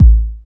Kick Slam 5.wav